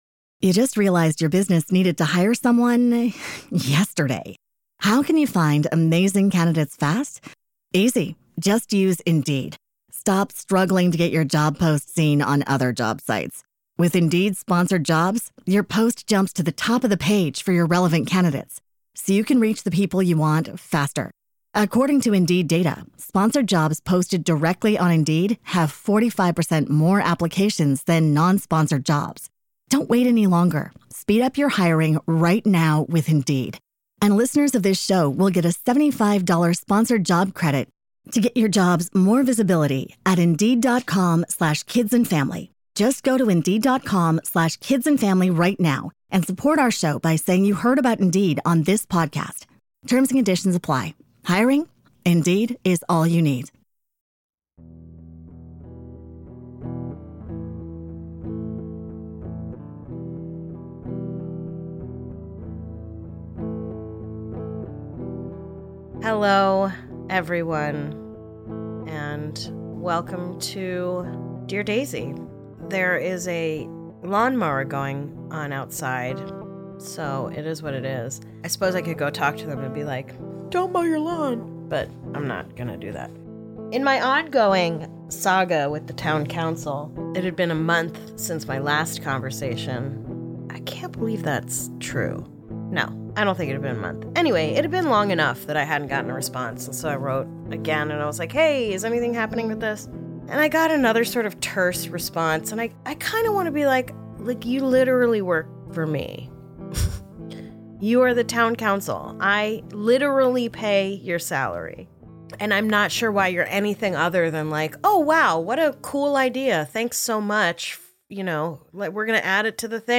Dear Daisy: Sh*t Happens, a dramatic reading by Daisy Eagan
A podcast about secrets hosted by Daisy Eagan.